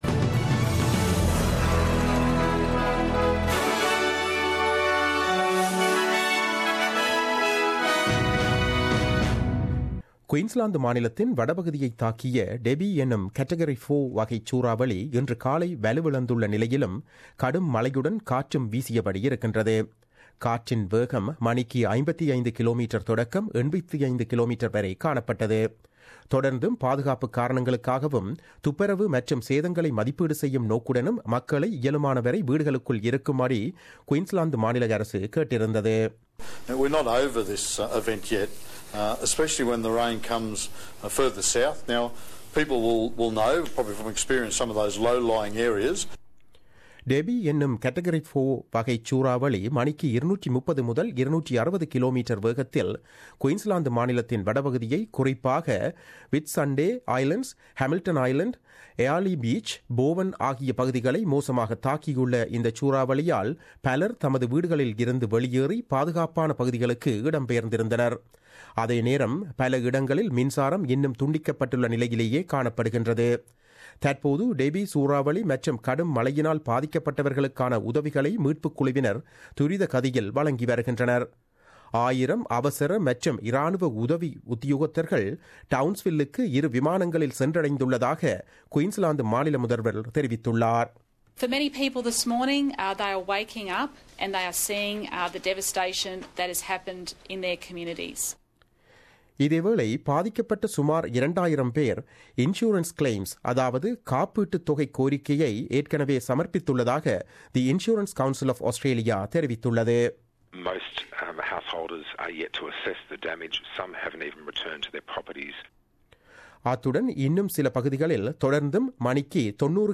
The news bulletin aired on Wednesday 29 March 2017 at 8pm.